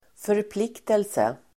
Uttal: [förpl'ik:telse]